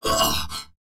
文件 文件历史 文件用途 全域文件用途 Enjo_dmg_02_3.ogg （Ogg Vorbis声音文件，长度0.8秒，134 kbps，文件大小：13 KB） 源地址:地下城与勇士游戏语音 文件历史 点击某个日期/时间查看对应时刻的文件。